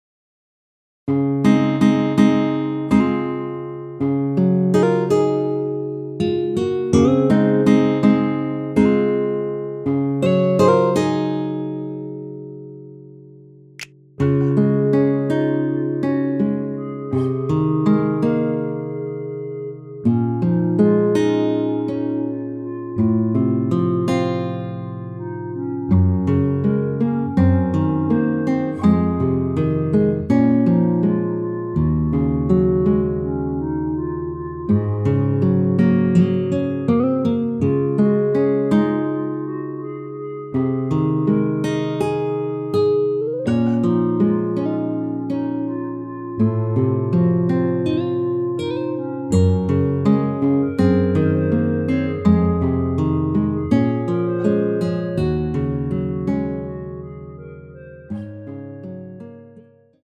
원키에서(-4)내린 멜로디 포함된 MR입니다.
Db
앞부분30초, 뒷부분30초씩 편집해서 올려 드리고 있습니다.
중간에 음이 끈어지고 다시 나오는 이유는